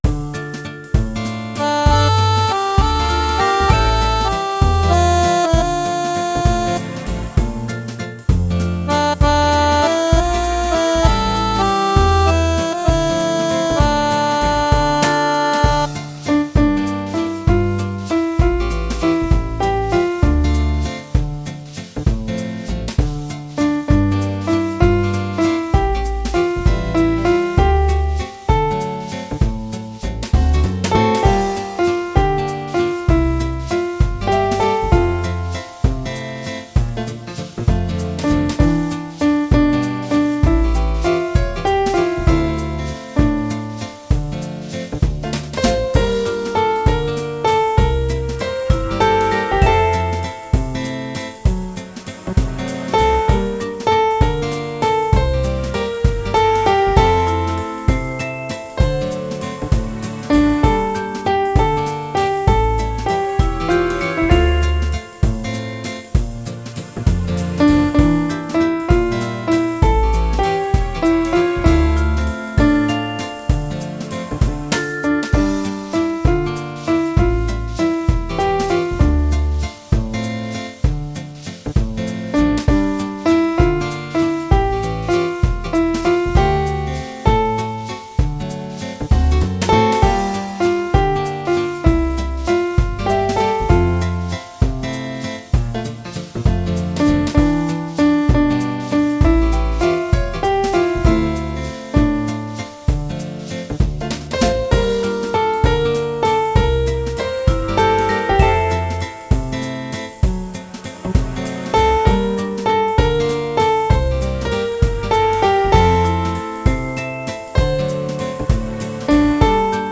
Рубрика: Поезія, Авторська пісня
Який гарний мрійливий вальс! love18 Чудово! 12 19 22 give_rose